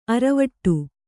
♪ aravaṭṭu